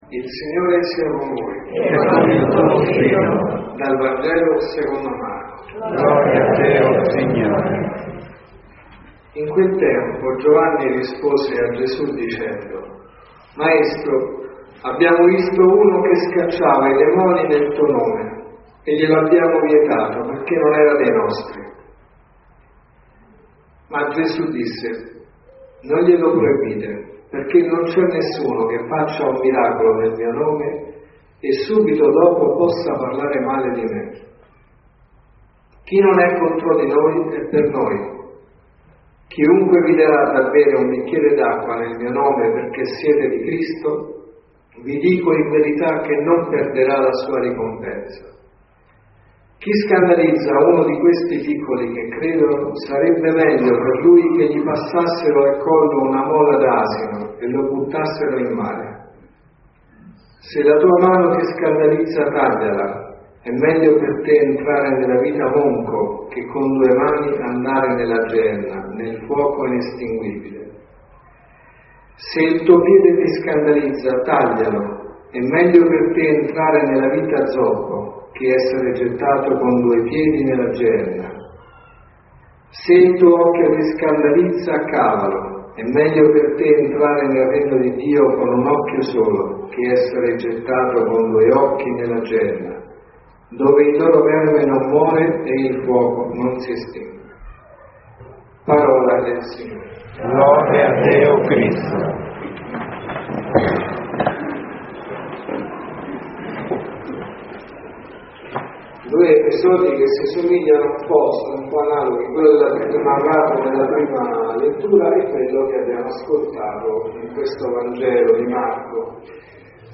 Se la tua mano ti è motivo di scandalo, tagliala | Omelie Messa della mattina LETTURE: Vangelo, Prima lettura e Seconda lettura Alleluia, alleluia.